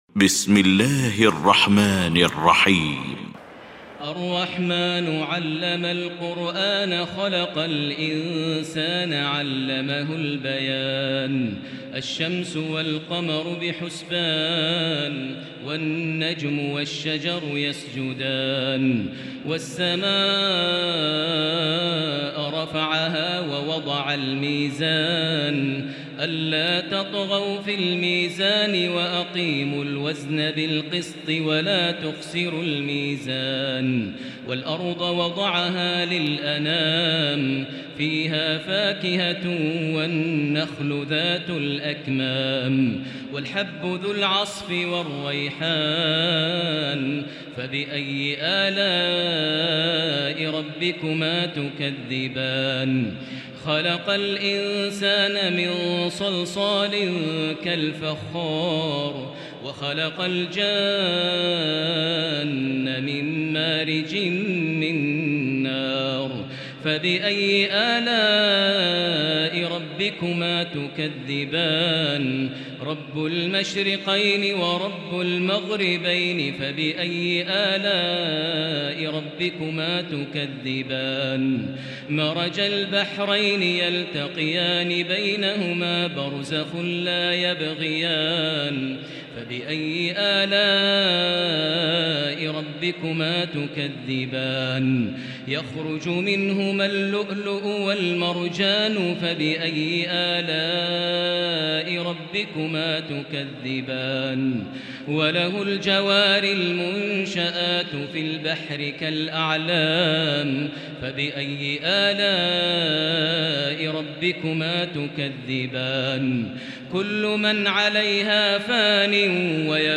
المكان: المسجد الحرام الشيخ: فضيلة الشيخ ماهر المعيقلي فضيلة الشيخ ماهر المعيقلي الرحمن